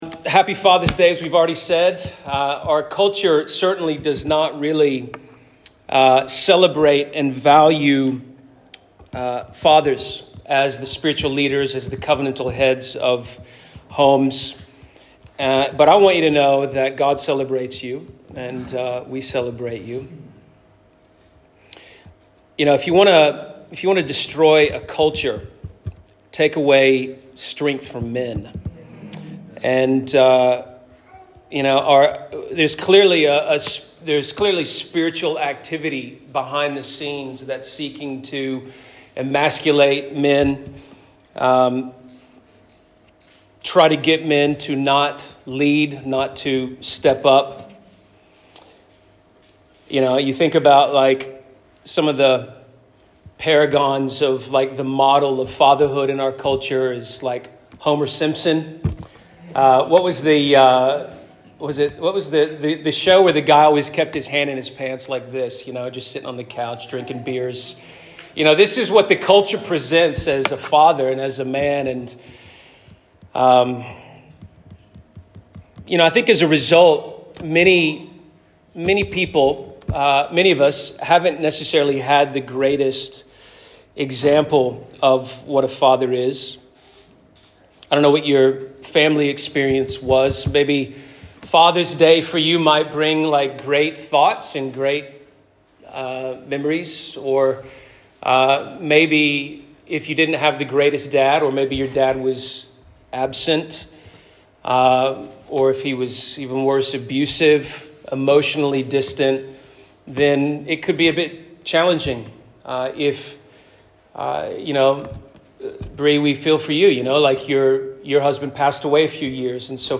ASCEND Church Perth